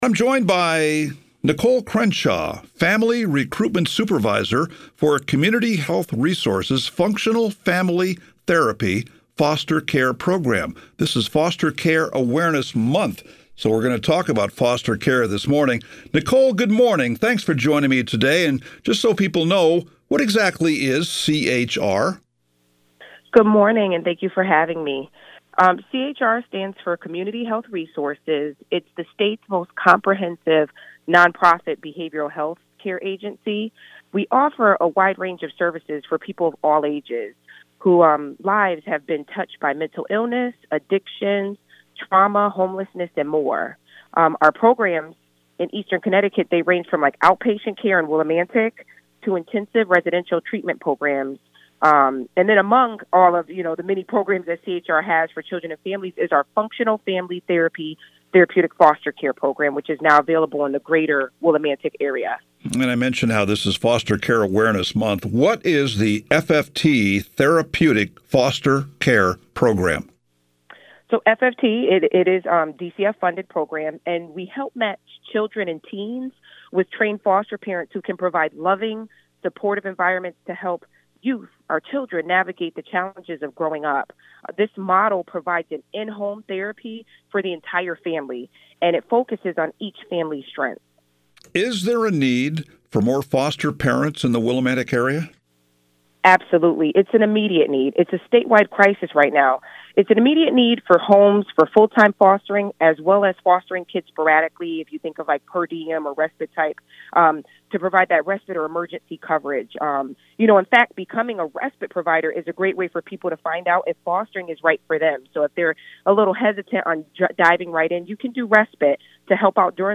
interviewed with WILI and Bomba Radio this May for Foster Care Awareness Month.